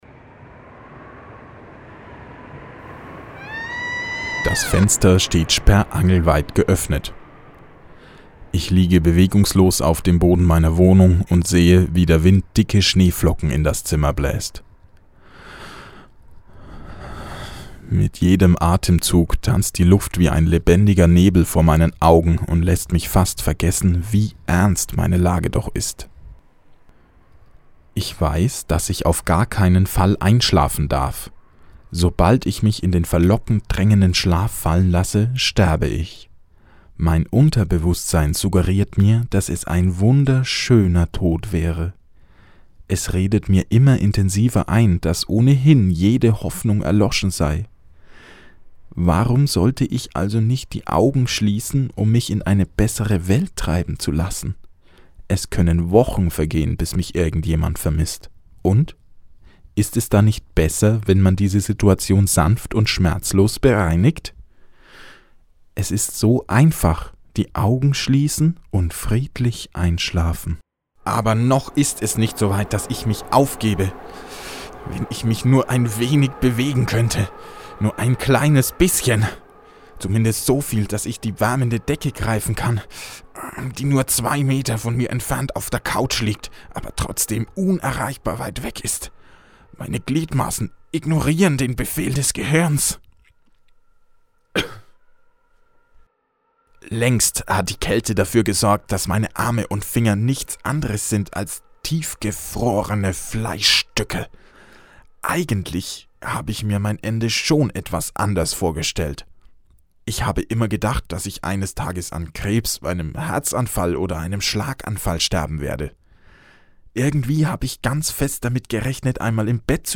20. Hörspiel
hoerspiel_americanLifestyle_teil1.mp3